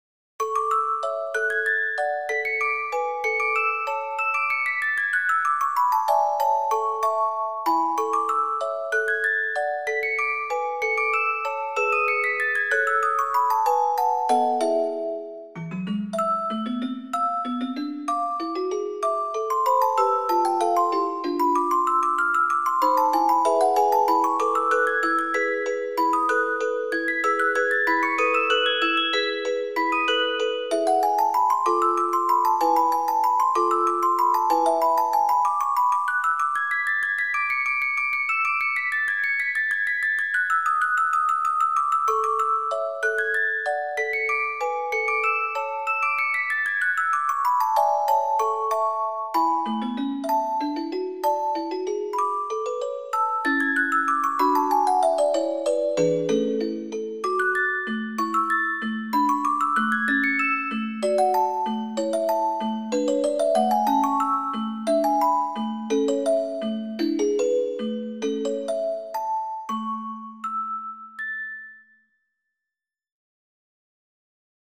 クラシック曲（作曲家別）－MP3オルゴール音楽素材